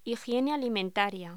Locución: Higiene alimentaria